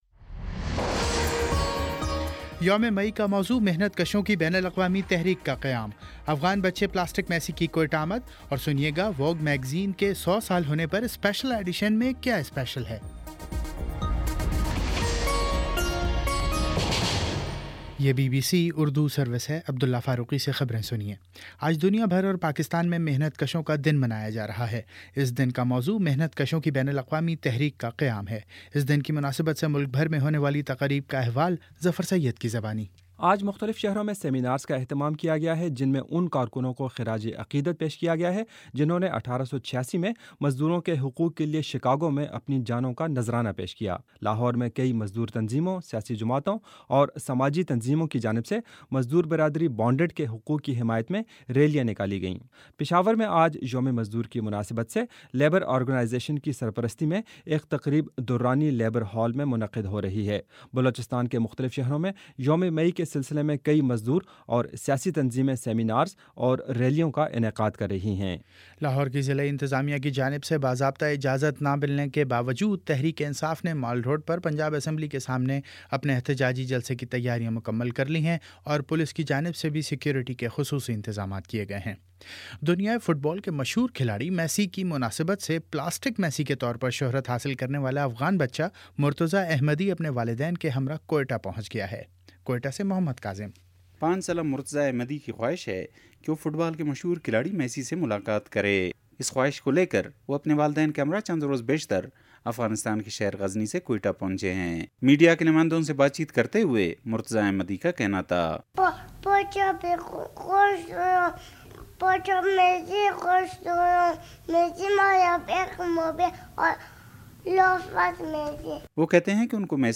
مئی 01 : شام چھ بجے کا نیوز بُلیٹن